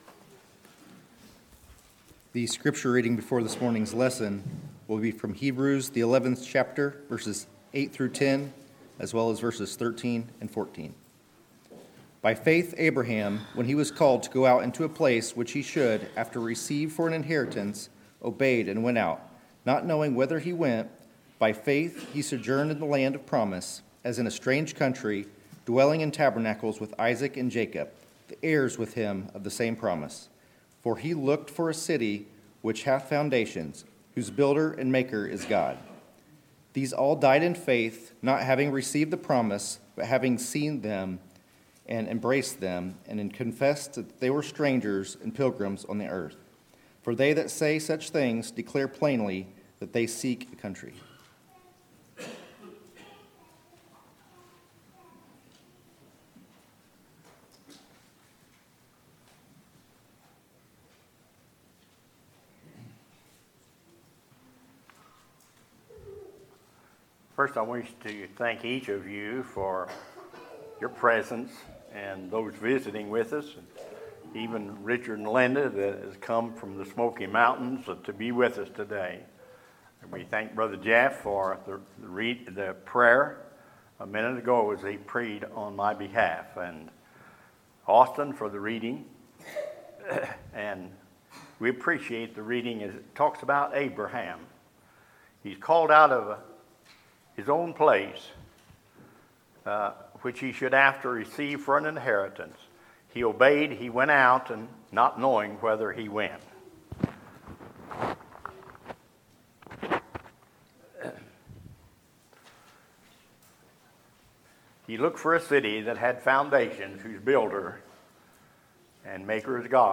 Sermons, October 28, 2018